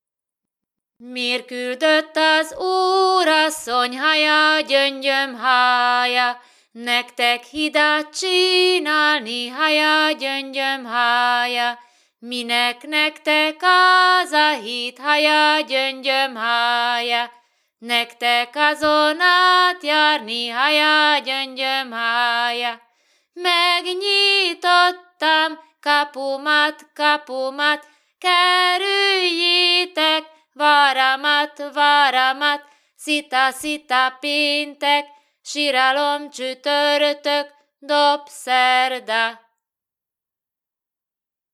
Virágvasárnap délután, amikor a leányok a kiszebábot bedobják a vízbe, két csoportba állnak, és énekelnek.
TípusI. Népi játékok / 07. Vonulások
TelepülésIpolybalog [Balog nad Ipľom]